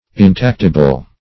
Search Result for " intactible" : The Collaborative International Dictionary of English v.0.48: Intactible \In*tac"ti*ble\, Intactable \In*tac"ta*ble\, a. Not perceptible to the touch.